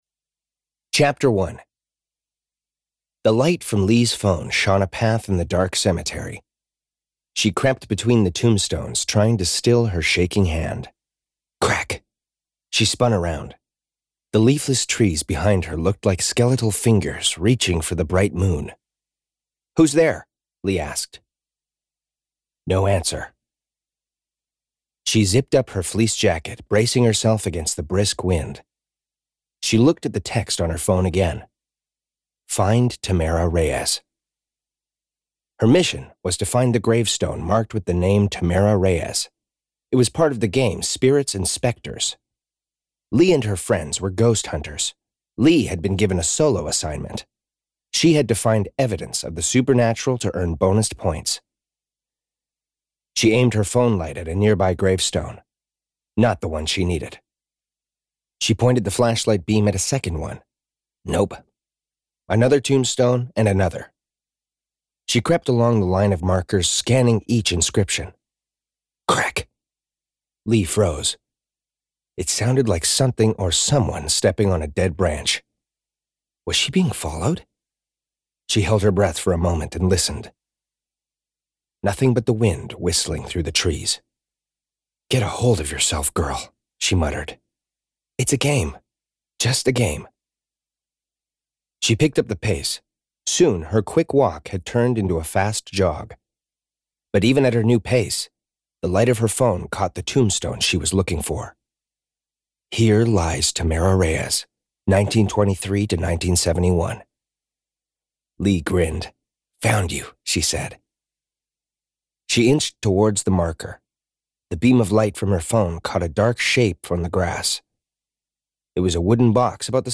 Download the Haunted Hospital Audio Sample.
hauntedhospital_audiosample.mp3